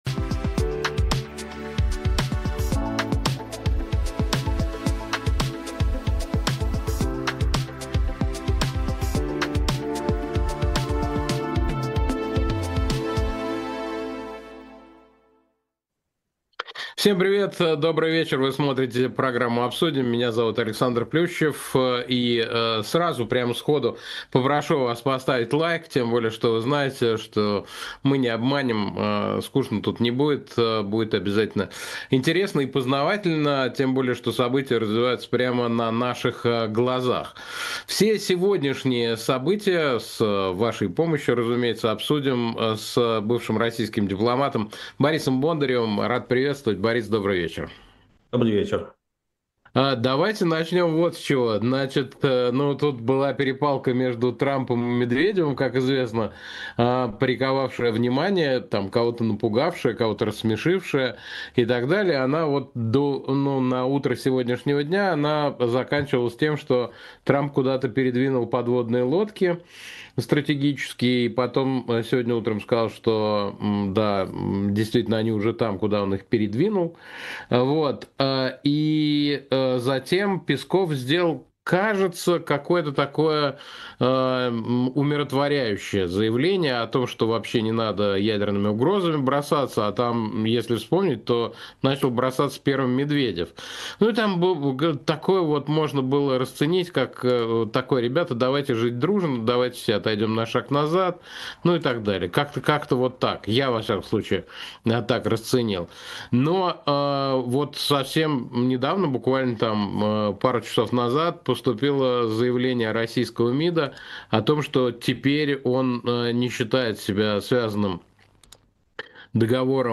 Эфир ведёт Александр Плющев
Гость выпуска — бывший российский дипломат Борис Бондарев. Обсудим с ним, что ждать от приезда Уиткоффа, почему Индия не слушается Трампа, можно ли предъявлять Швейцарии за Матвиенко, и другие темы.